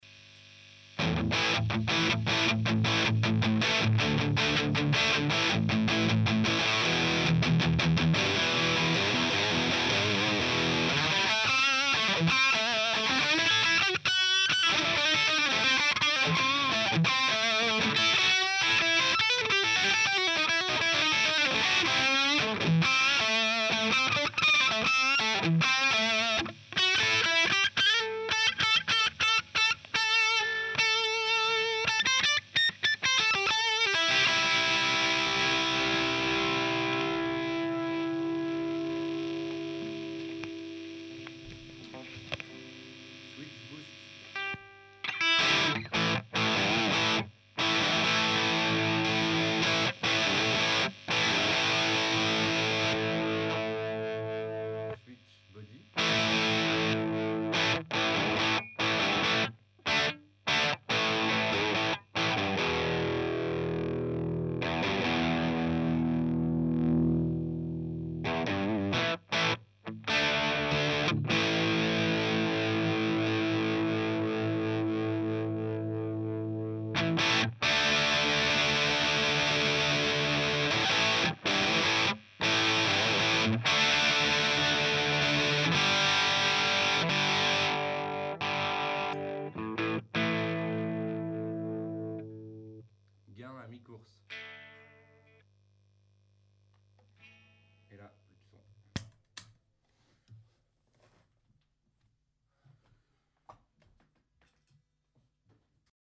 J'ai le meme probleme avec le canal blue, dont voici un sample :
C'est un peu trop harsh pour moi, meme en coupant des aigus il en reste ...
Sinon j'ai un ptit soucis, on l'entend sur le sample, a la fin, le 7812 chauffe trop meme avec son dissipateur (assez petit il est vrai), et se met en protection thermique apparement, car il se coupe, et remarche en refroidissant :(
c'est une prise de son avec un Sm-58 devant le HP, un celestion classic lead 80, baffle 1x12 home made, preamp de la table de mix behringer, pas d'effort de placement particulier, parallele a la membrane du Hp, a 10cm environ ...